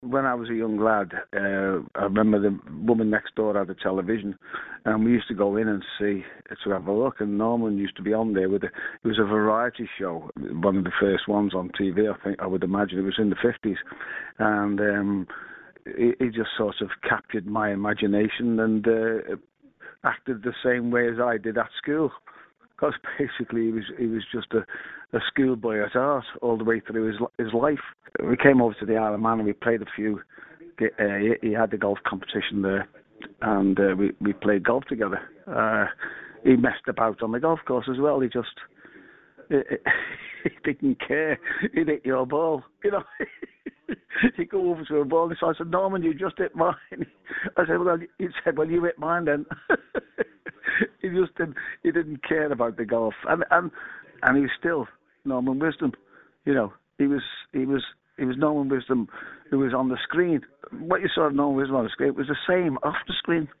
Comedian Stan Boardman Pays Tribute To Friend Sir Norman